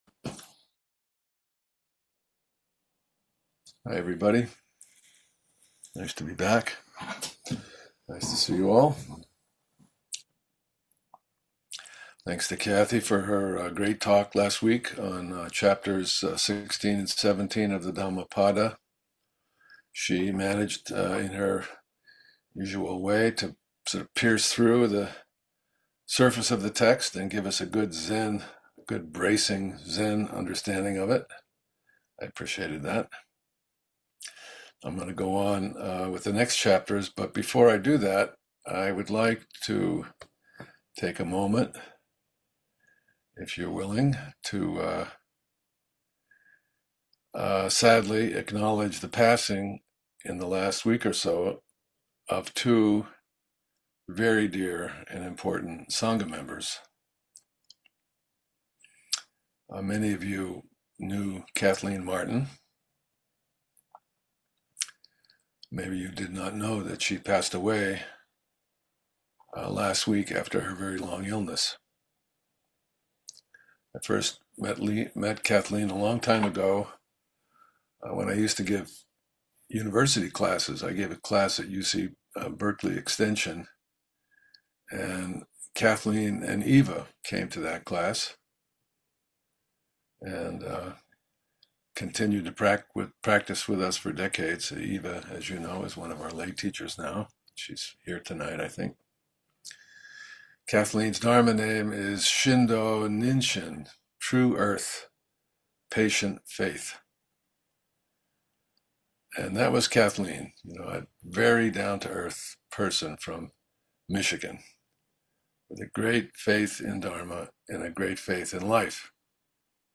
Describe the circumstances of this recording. gives the twelfth talk of the Dhammapada series to the Everyday Zen dharma seminar. The Dhammapada or “Path of Dharma” is a collection of verses in the Pali Canon that encapsulates the Buddha’s teachings on ethics, meditation and wisdom and emphasizes practical guidance for living a virtuous life.